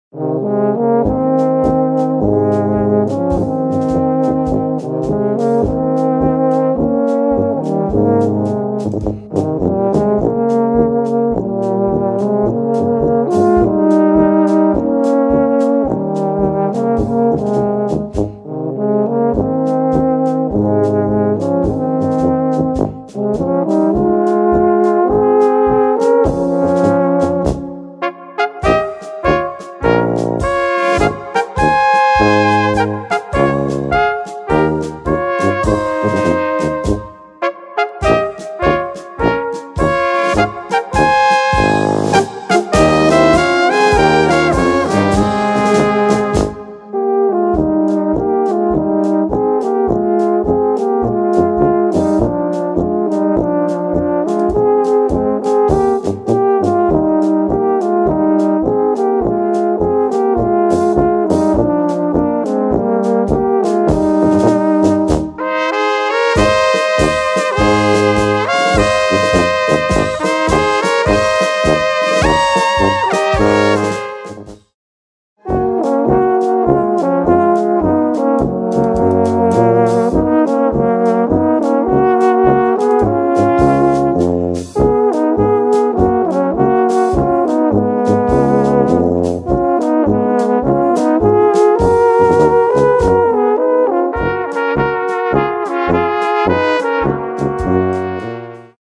Gattung: Polka
Besetzung: Blasorchester
Flügelhörner, Tenor und Bariton, Tuba und Schlagzeug